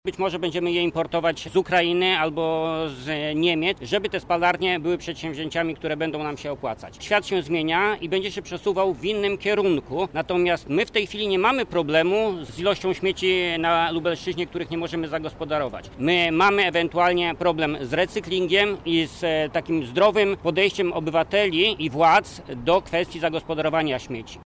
Jeżeli takie miejsce powstanie, to ja się zastanawiam, skąd będziemy pozyskiwać te śmieci – mówi senator Jacek Bury z Polski 2050: